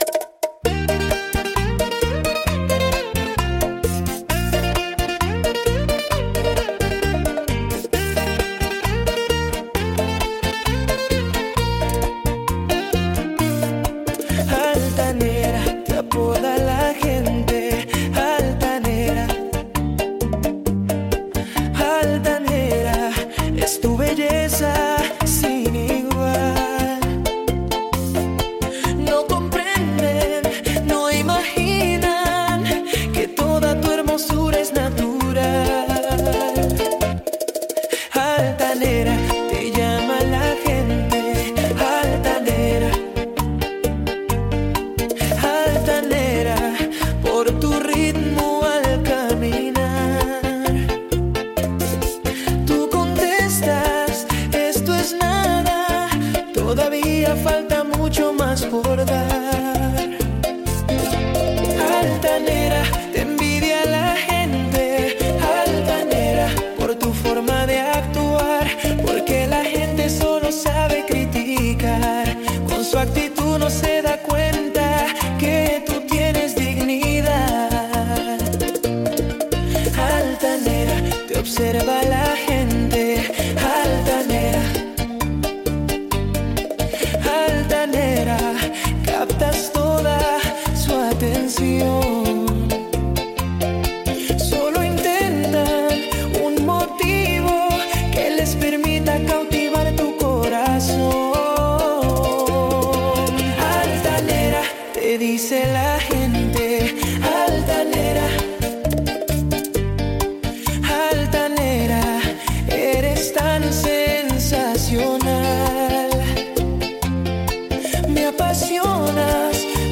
Genre Bachata